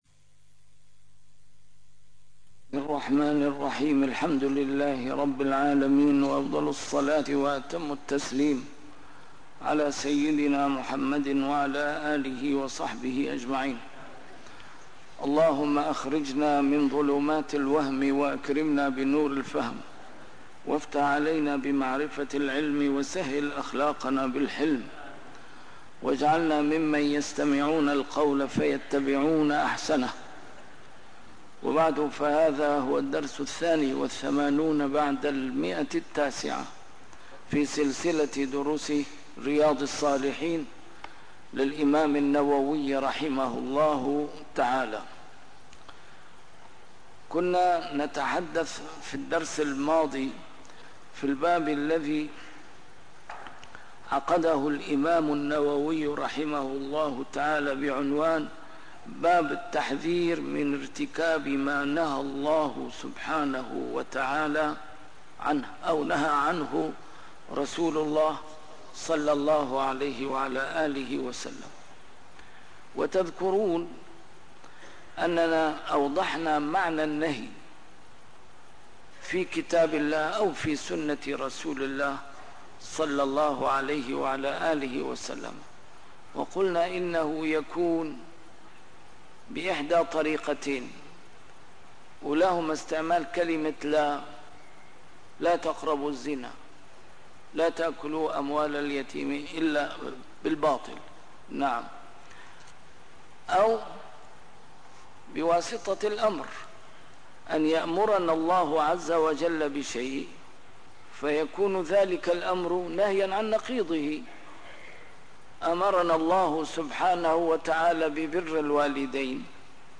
A MARTYR SCHOLAR: IMAM MUHAMMAD SAEED RAMADAN AL-BOUTI - الدروس العلمية - شرح كتاب رياض الصالحين - 982- شرح رياض الصالحين: البدعة اللغوية والشرعية ما يقوله ويفعله من ارتكب منهياً